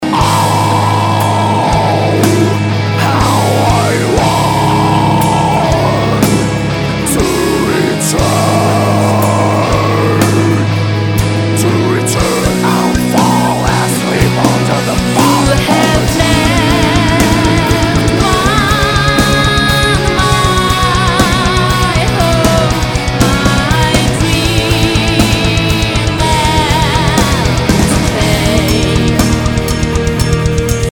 Музыка » Rock » Rock